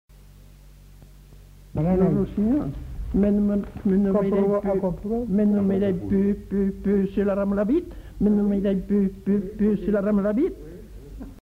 Aire culturelle : Haut-Agenais
Genre : forme brève
Effectif : 1
Type de voix : voix d'homme
Production du son : récité
Classification : mimologisme